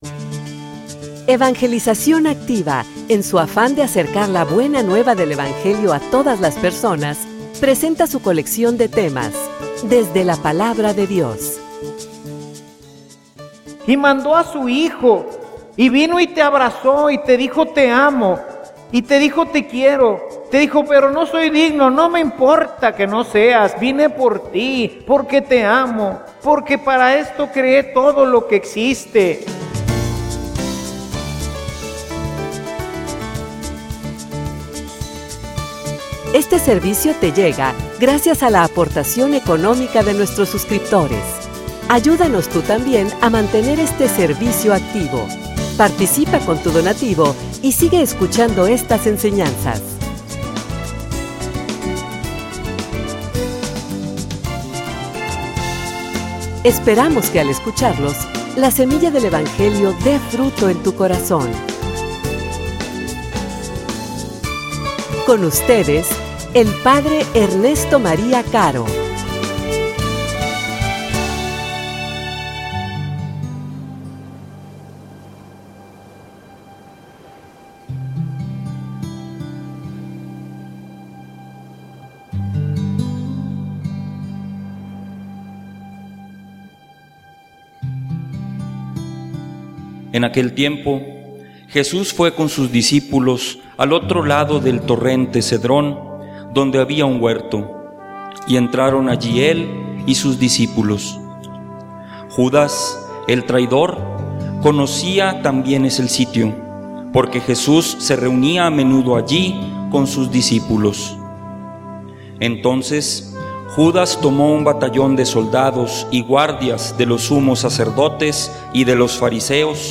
homilia_Nos_amo_y_nos_perdono.mp3